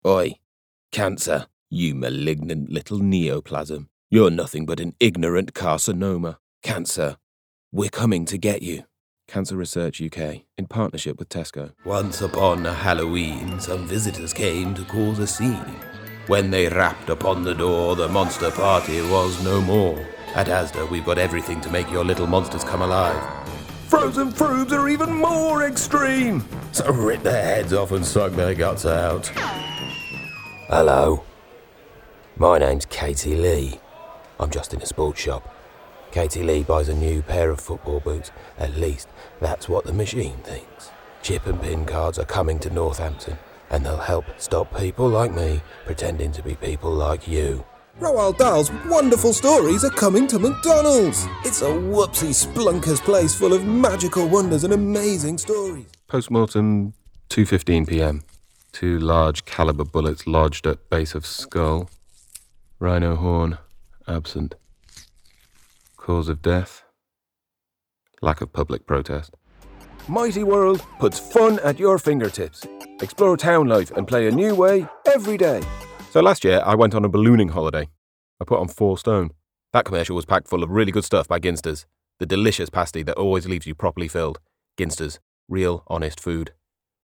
Male
English (British)
Yng Adult (18-29), Adult (30-50)
My voice is characterful and clear, naturally British, mid deep with a soft, native Yorkshire/northern accent.
Within my range I can pivot between gravelly and mysterious storyteller to a friendly and warm conversational style that's both upbeat, and humorous.
Tv Narration Adverts
0109Commercial_Showreel_2.mp3